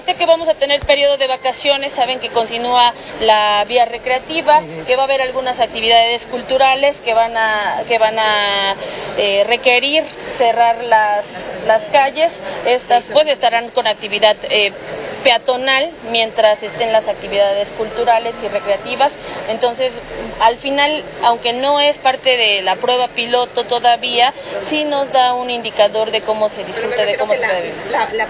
“Ahorita que vamos a tener periodo de vacaciones saben que continúa la vía recreativa, que va a haber algunas actividades culturales que van a requerir cerrar las calles pues estarán con actividad peatonal, mientras estén las actividades culturales y recreativas entonces al final aunque no es parte de la prueba piloto todavía, si nos da un indicador de cómo se disfruta”, sentenció en entrevista posterior a participar en la puesta en marcha de el “Operativo Vacaciones Seguras Invierno 2019”, la cual se efectuó en el zócalo de la ciudad.